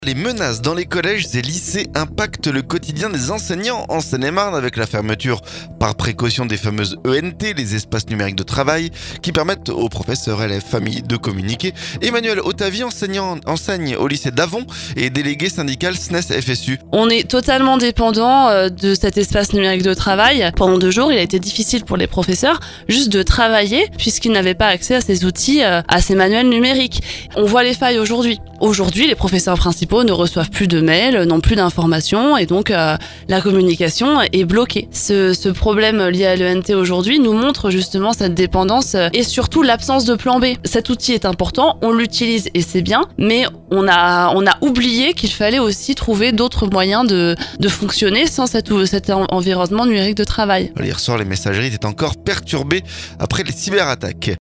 EDUCATION - Cette enseignante témoigne des difficultés liées à la fermeture des ENT